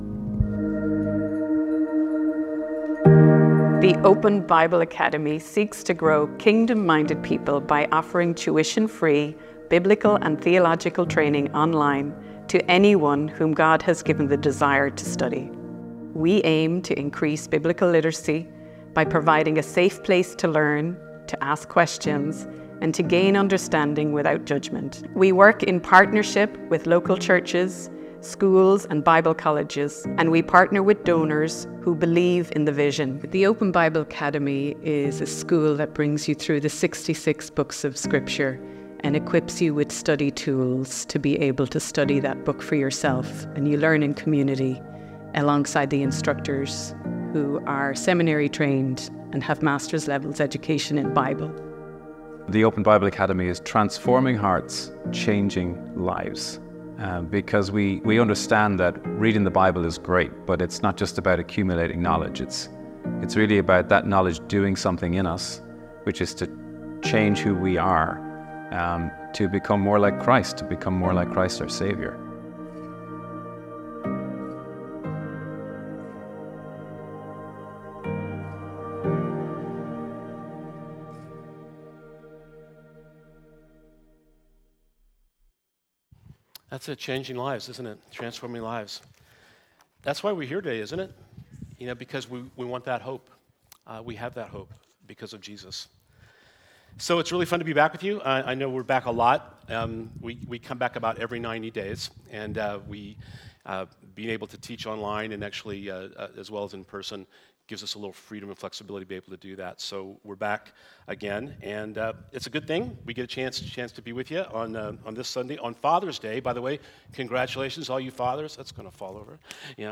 Passage: Eph 3:14-20 Service Type: Sunday